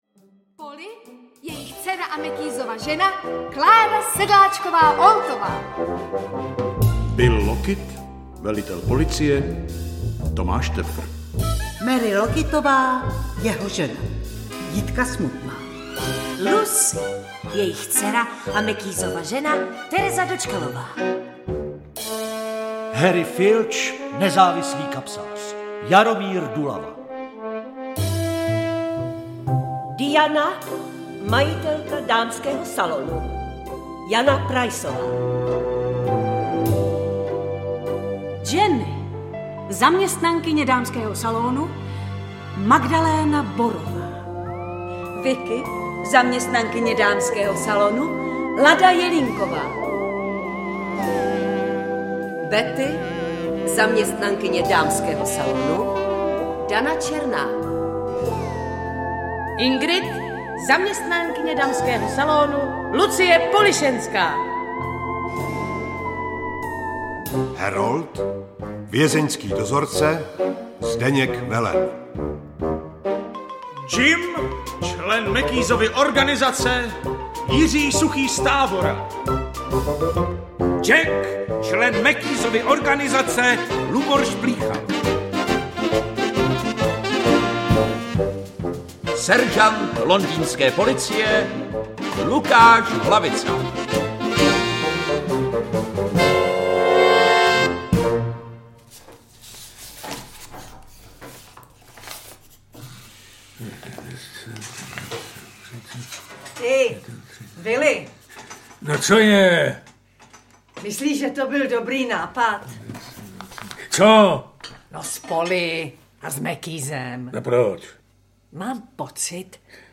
Žebrácká opera audiokniha
Ukázka z knihy